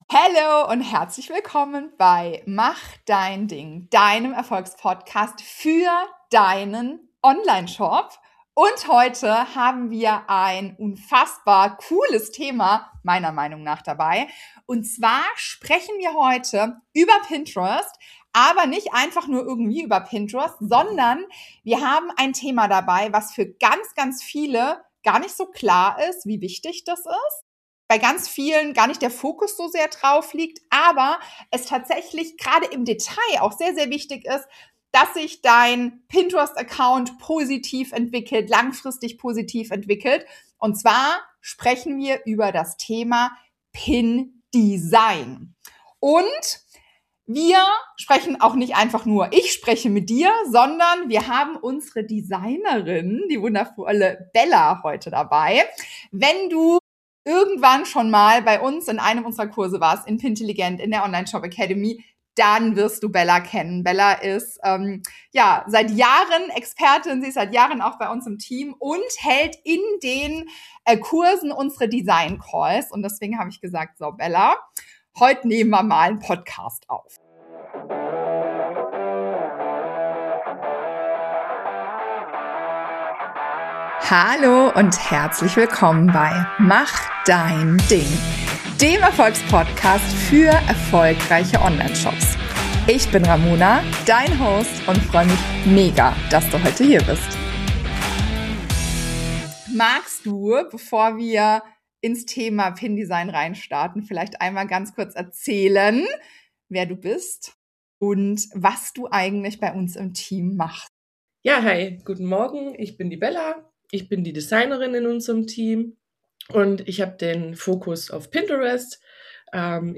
Pin Design Hacks: So performen deine Pins wirklich — Interview